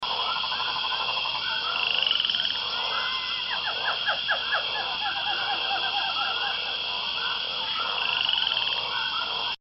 Sounds of the Jungle - Sonidos de la selva
06-sounds-of-the-jungle.mp3